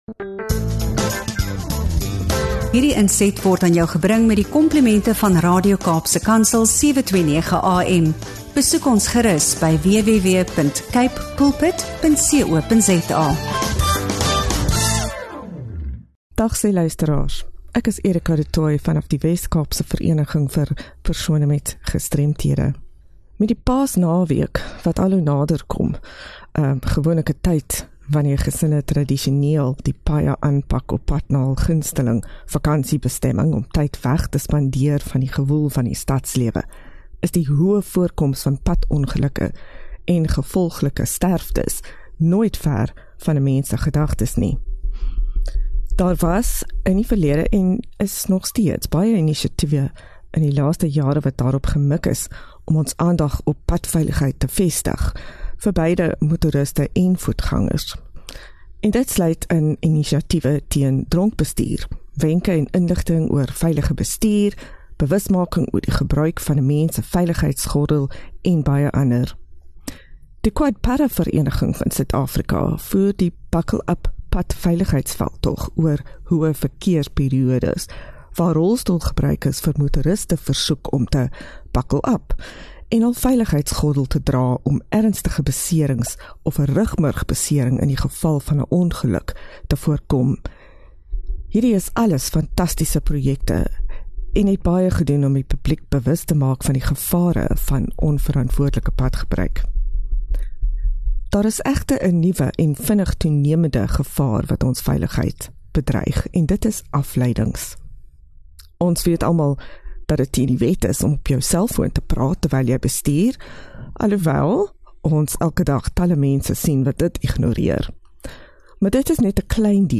Hierdie gesprek bring die harde realiteit van lewensveranderende beserings na vore en moedig elkeen aan om verantwoordelik en bewustelik agter die stuur in te klim.